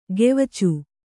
♪ gevacu